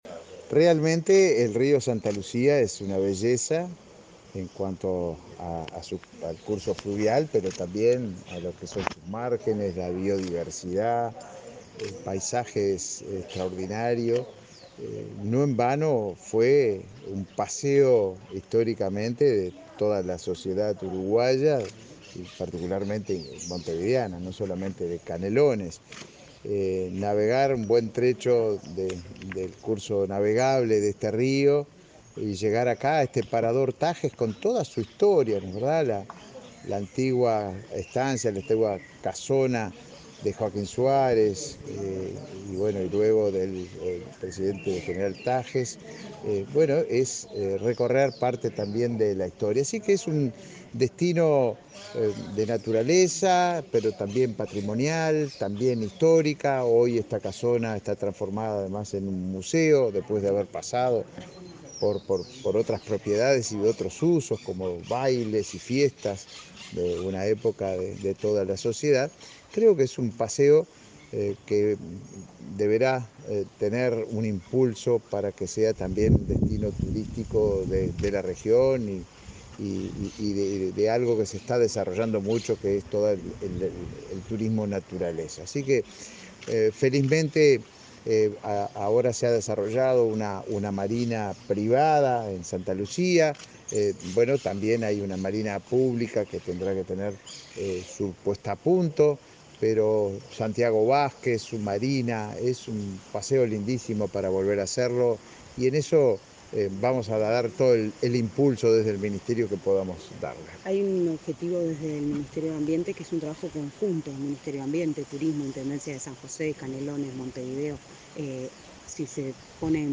Declaraciones del ministro de Turismo y el subsecretario de Ambiente